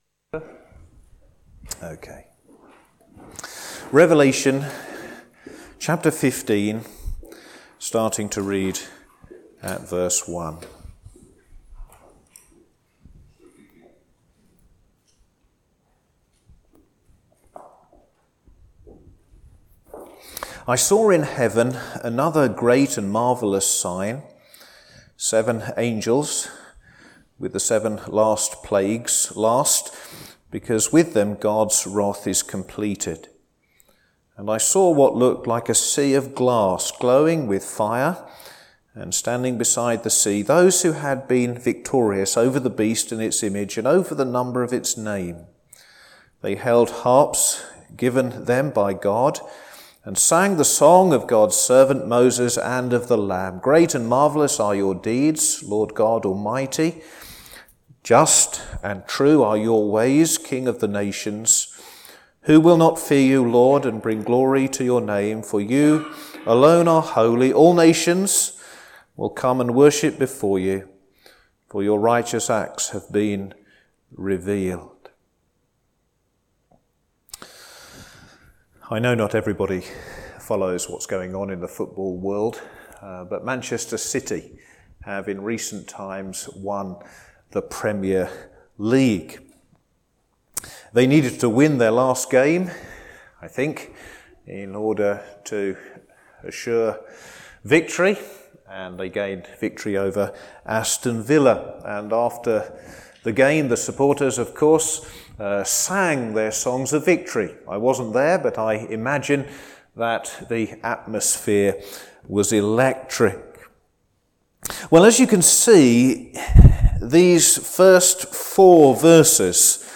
Sermon
Service Evening